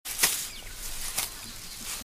割草.MP3